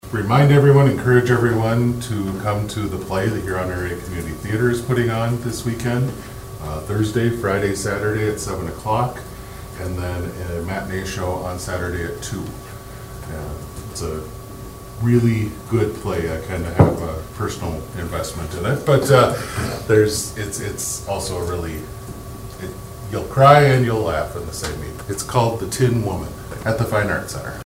PUBLIC FORUM INFORMATION AT CITY COMMISSION MEETING